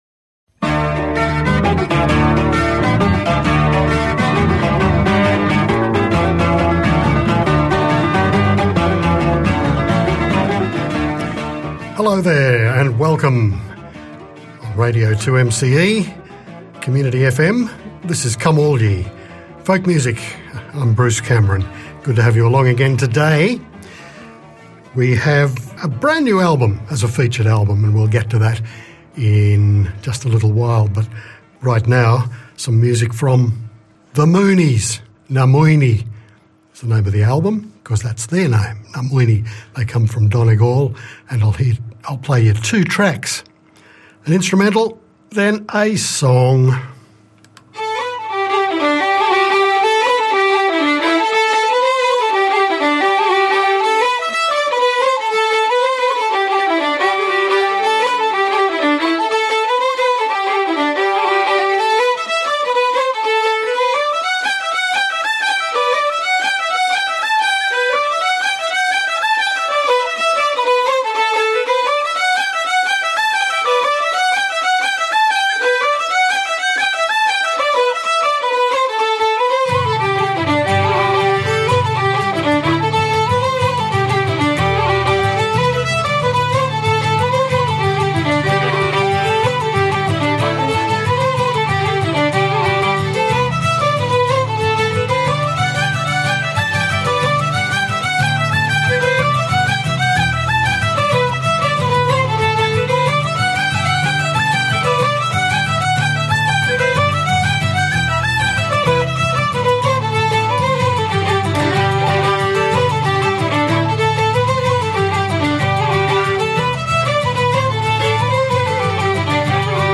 A stringband from